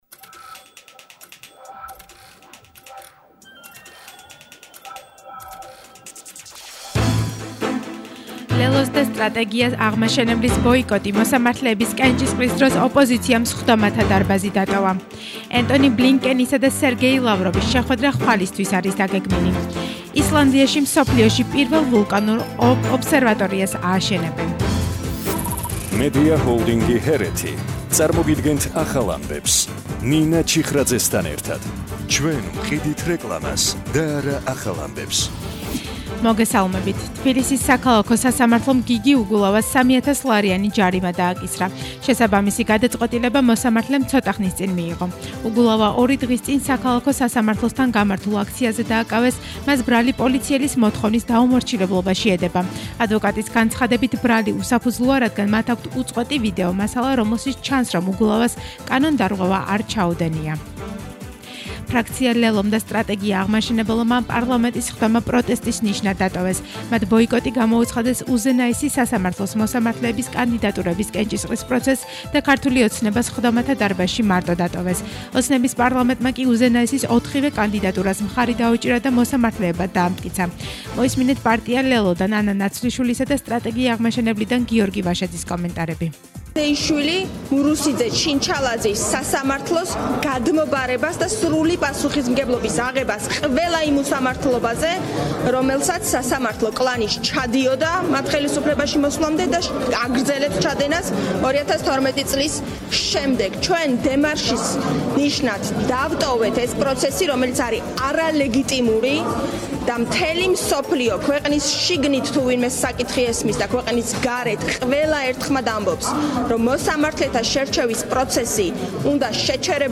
ახალი ამბები 15:00 საათზე –1/12/21 – HeretiFM